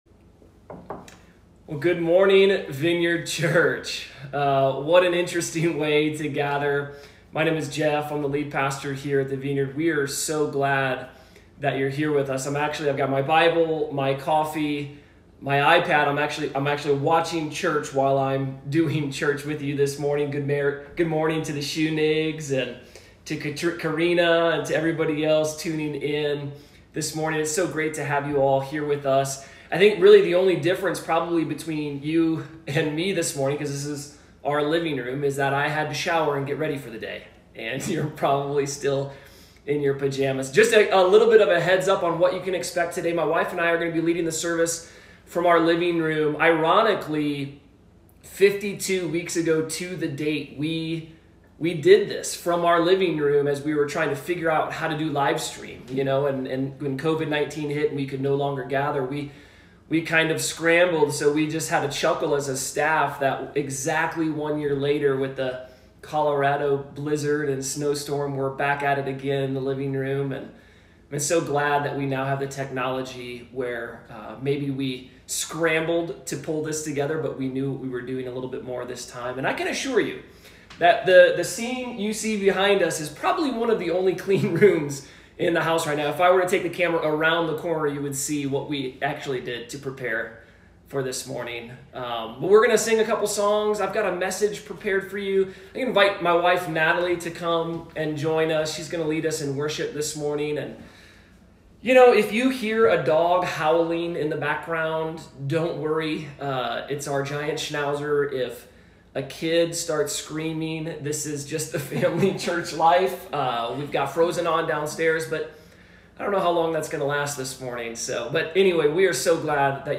In this sermon we’ll look at the words of Christ that call us to a deeper, more dedicated, and sold out relationship with Jesus that is 100% focused on following Him. Being a fan of Jesus is easy—being a follower is costly.